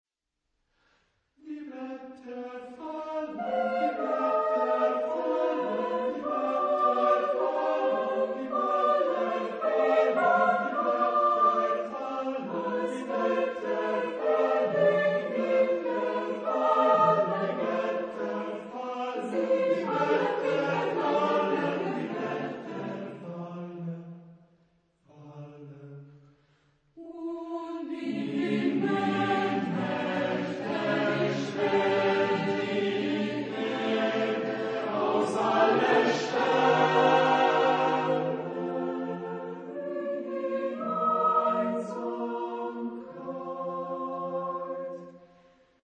Género/Estilo/Forma: contemporáneo ; Lied
Carácter de la pieza : allegretto ; trastornado
Tipo de formación coral: SATB  (4 voces Coro mixto )